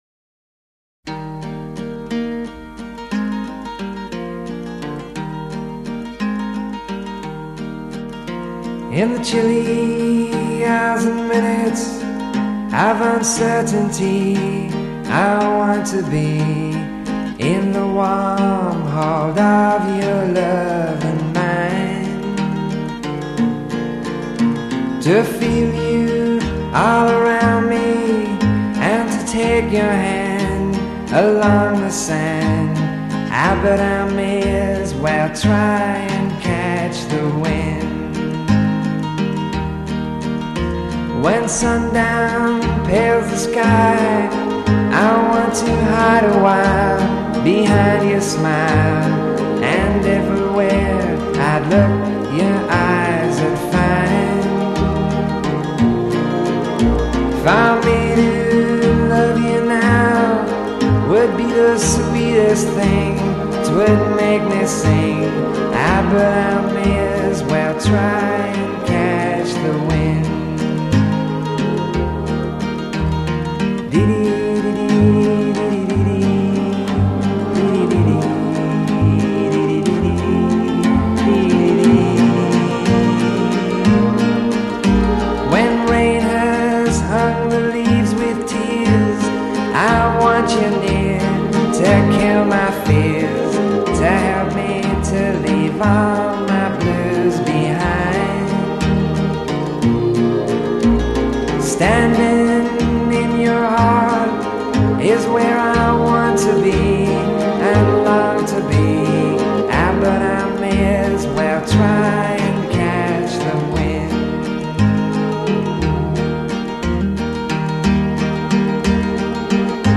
Recorded at Peer Music, Denmark Street, London.
Introduction   Acoustic guitar
Verse   As above, add strings (?) b
Bridge   Non-lexical syllables.
Coda   Repetition of half stanza music without text.